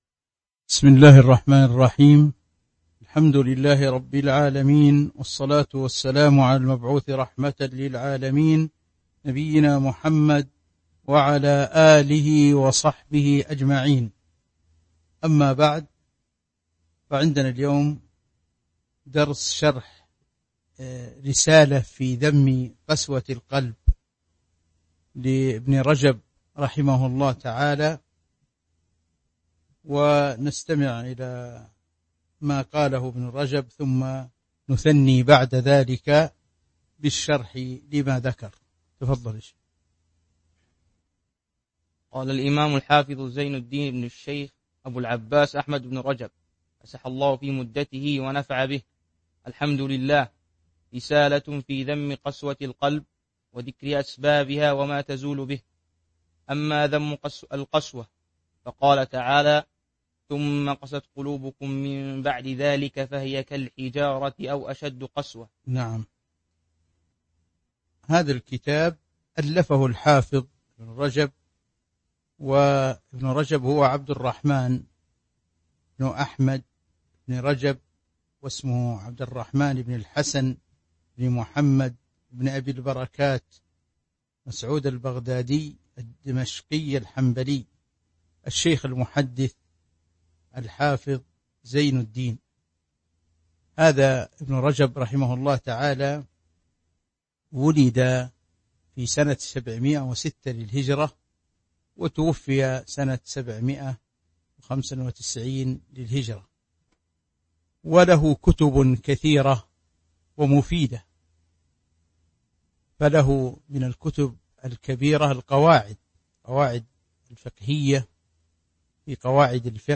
تاريخ النشر ٧ ذو القعدة ١٤٤٢ هـ المكان: المسجد النبوي الشيخ